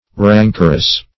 Rancorous \Ran"cor*ous\ (r[a^][ng]"k[~e]r*[u^]s), a. [OF.